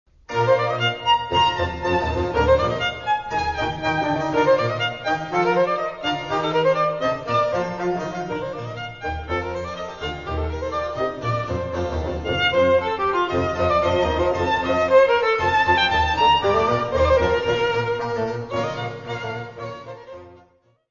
: stereo; 12 cm
Área:  Música Clássica